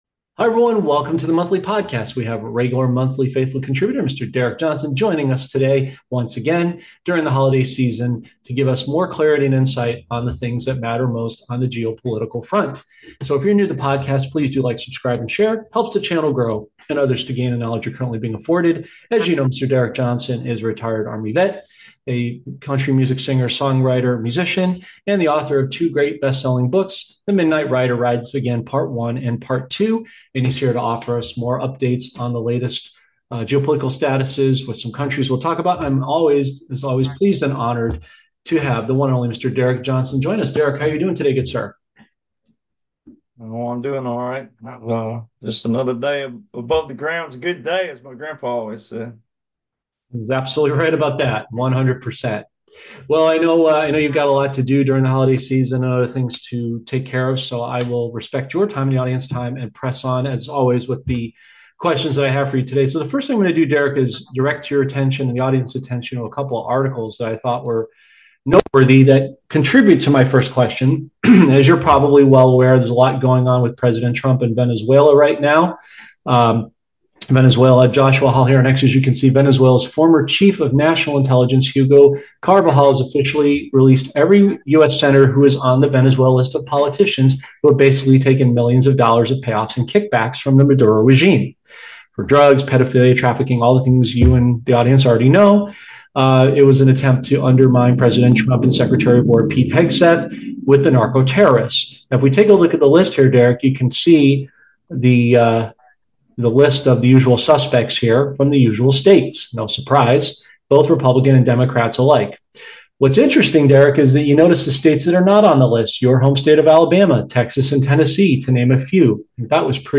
He discusses the global impact of these issues and emphasizes the importance of integrity in politics. The conversation also explores historical doctrines, military strategies, and the significance of individual rights in shaping a hopeful future.